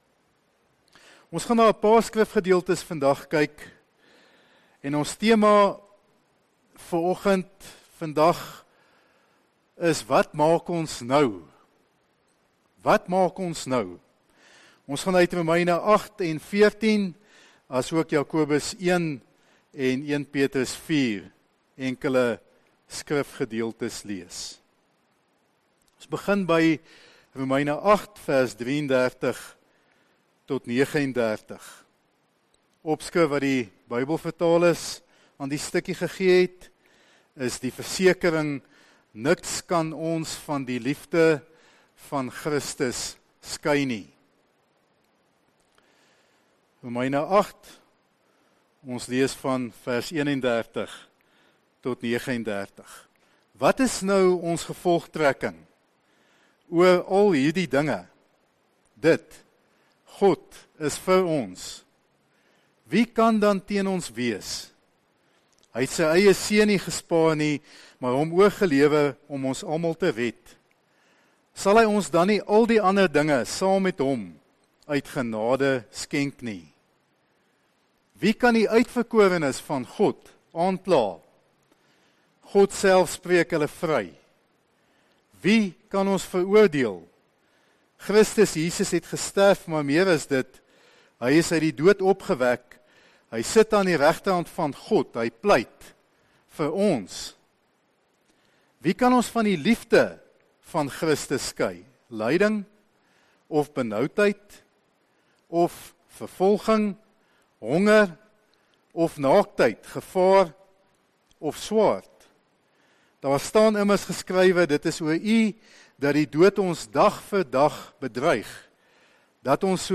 Erediens - 17 Januarie 2020